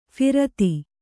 ♪ phirati